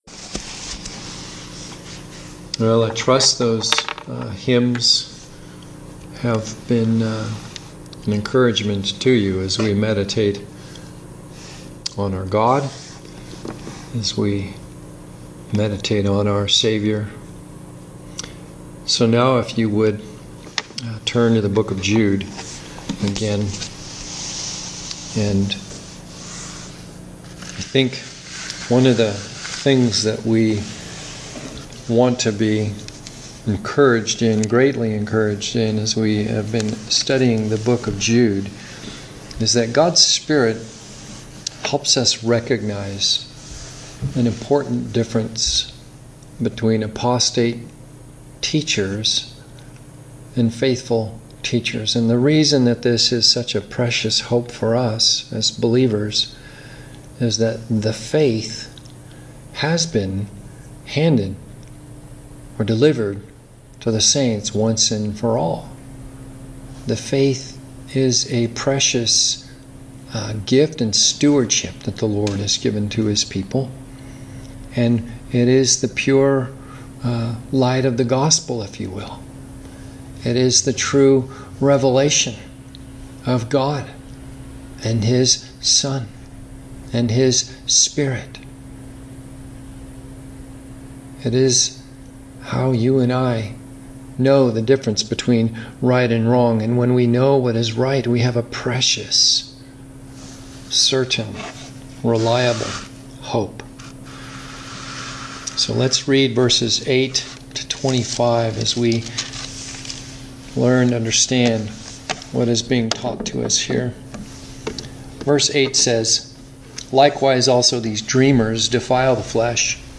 3/15 – Sunday Worship in Preaching
Sermon